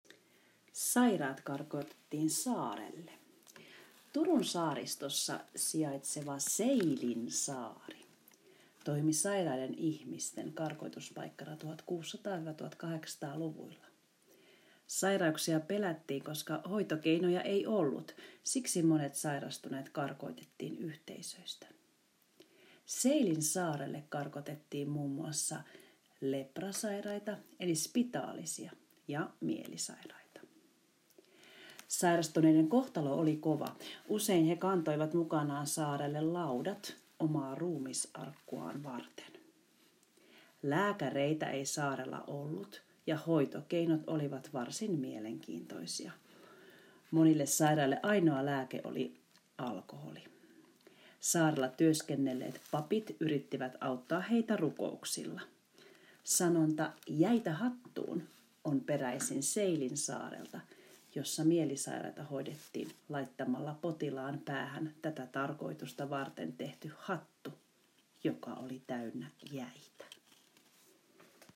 Kuuntele, mitä opettaja kertoo saaresta.